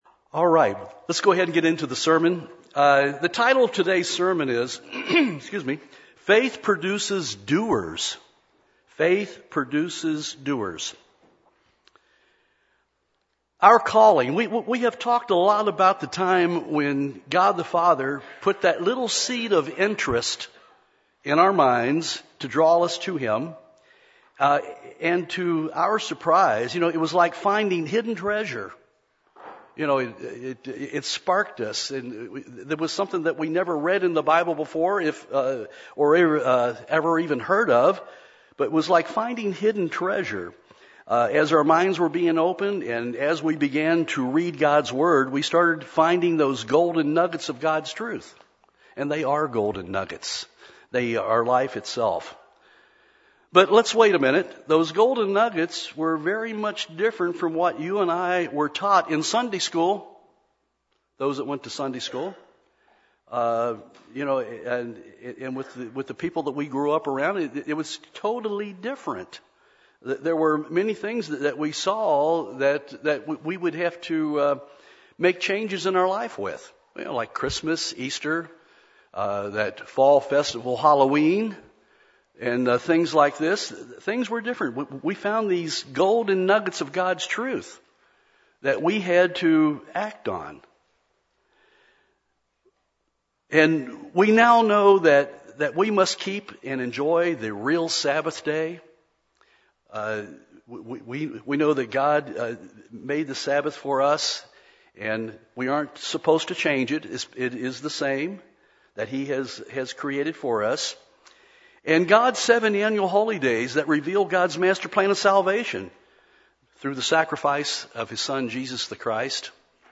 Sermon
Given in Nashville, TN